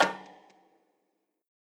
MB Perc (6).wav